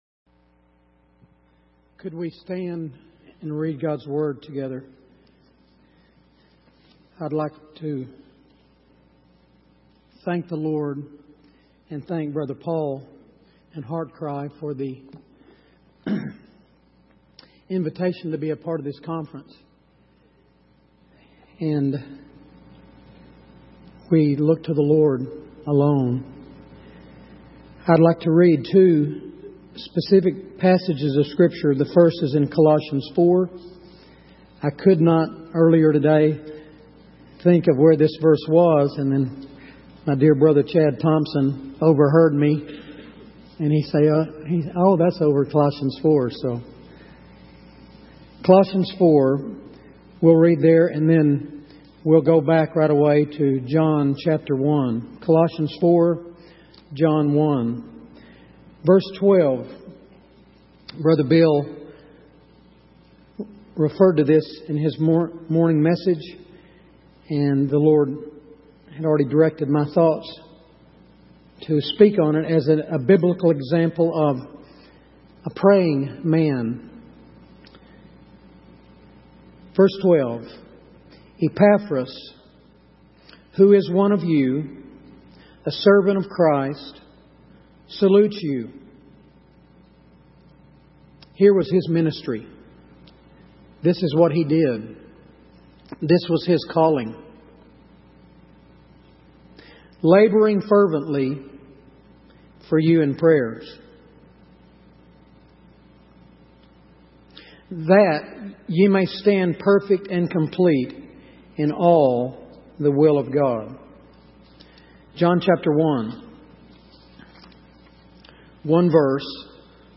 In this sermon, the preacher emphasizes the importance of the Holy Spirit in the lives of believers. He shares stories of individuals who experienced a powerful encounter with the Holy Spirit and were transformed as a result. The preacher also highlights the need for continual dependence on the Holy Spirit and the importance of prayer for His presence and guidance.